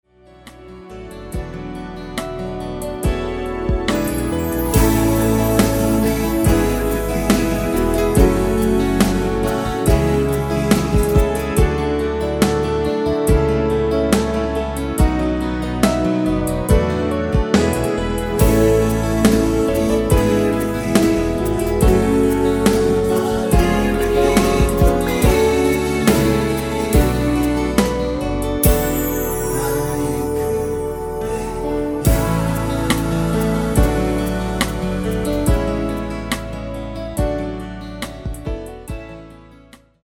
코러스 포함된 MR 입니다.(미리듣기 참조)
앞부분30초, 뒷부분30초씩 편집해서 올려 드리고 있습니다.
중간에 음이 끈어지고 다시 나오는 이유는